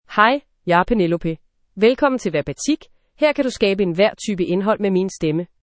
Penelope — Female Danish AI voice
Penelope is a female AI voice for Danish (Denmark).
Voice sample
Listen to Penelope's female Danish voice.
Penelope delivers clear pronunciation with authentic Denmark Danish intonation, making your content sound professionally produced.